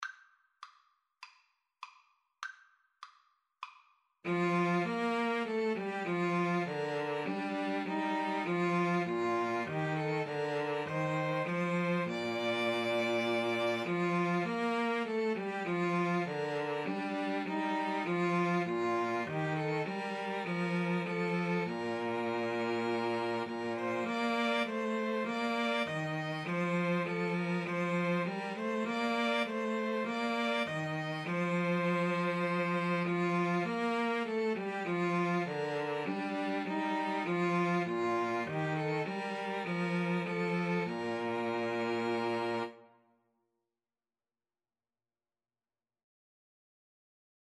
Free Sheet music for String trio
Bb major (Sounding Pitch) (View more Bb major Music for String trio )
Classical (View more Classical String trio Music)